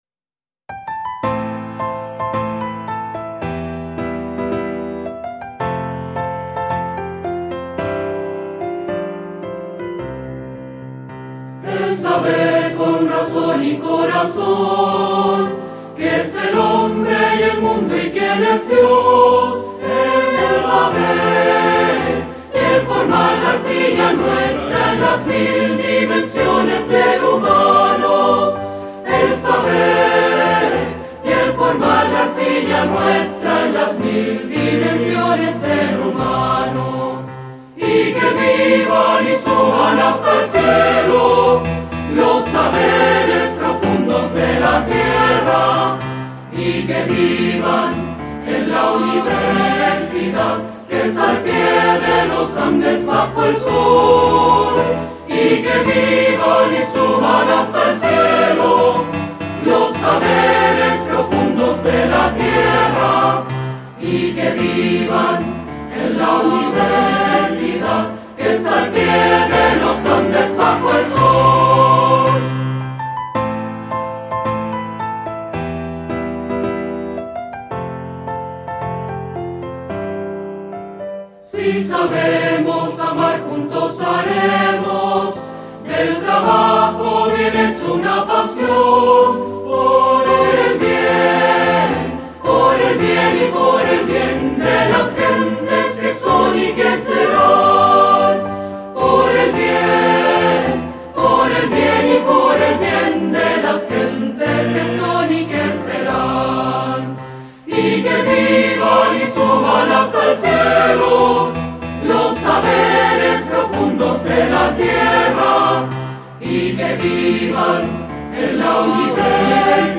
Coro Uandes en acto académico año 2015
Letra y música representada por el Coro de Alumnos